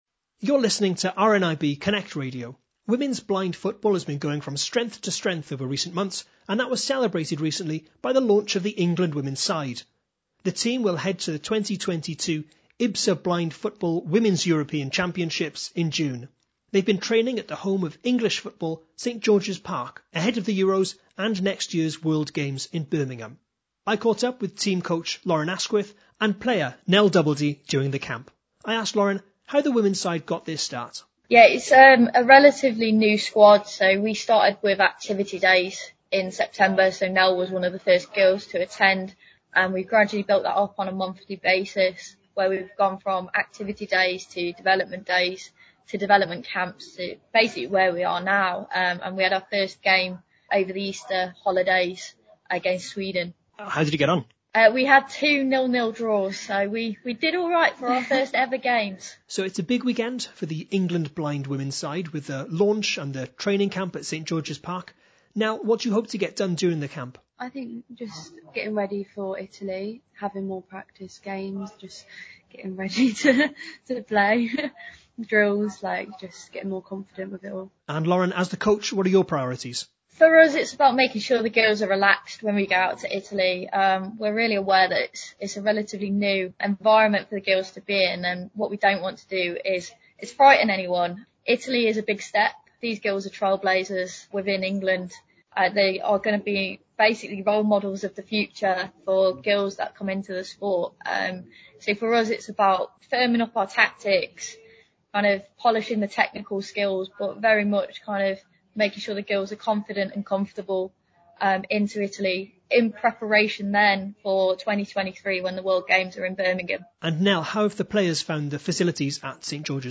spoke to the newly formed England Women's side ahead of the competition.